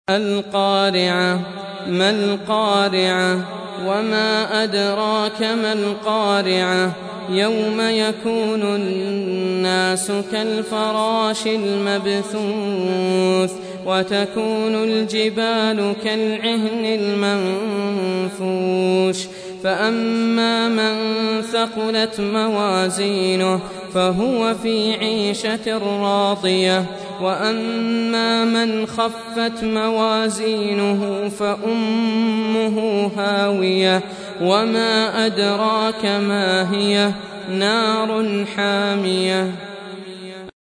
Surah Repeating تكرار السورة Download Surah حمّل السورة Reciting Murattalah Audio for 101. Surah Al-Q�ri'ah سورة القارعة N.B *Surah Includes Al-Basmalah Reciters Sequents تتابع التلاوات Reciters Repeats تكرار التلاوات